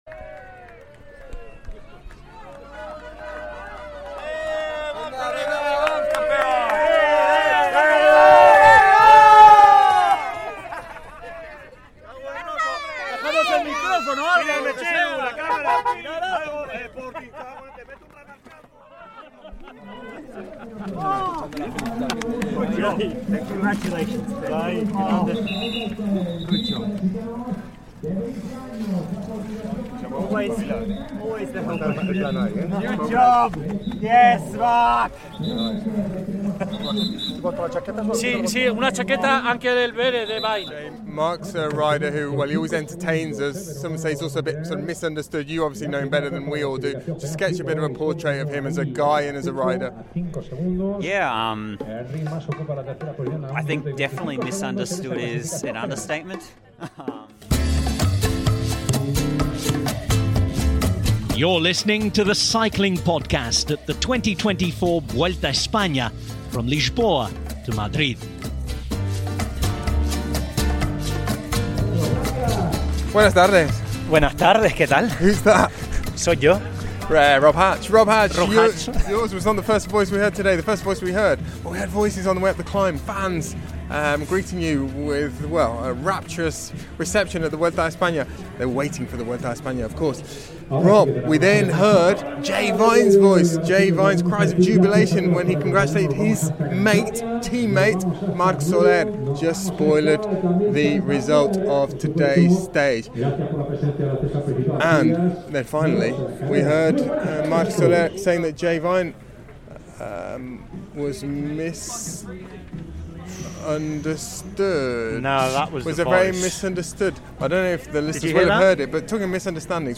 There’ll be analysis, interviews, wistful gazing and tepid takes from on the ground, in the thick of the action - and a return for both popular and unpopular features from previous editions!